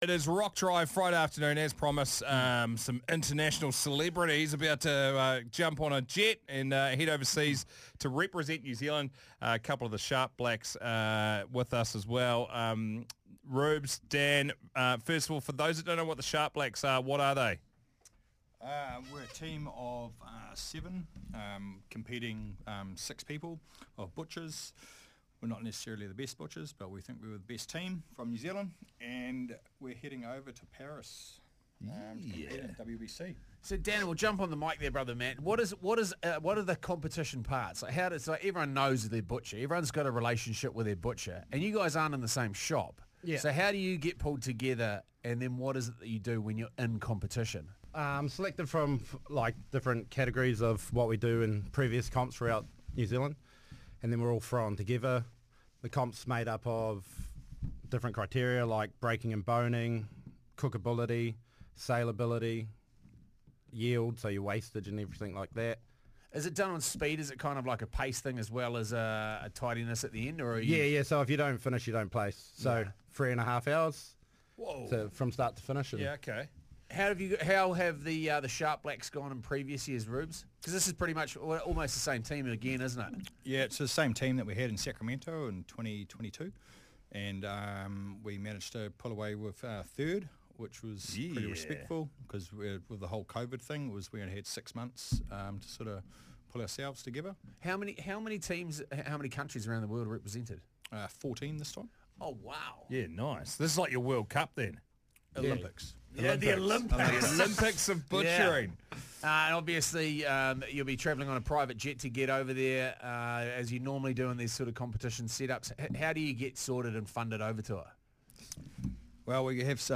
rkdrive-sharp-blacks-interview-YNqB5By4V5tlExNl.mp3